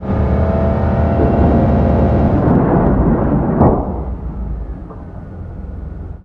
flip1.ogg